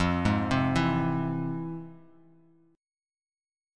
FmM7 Chord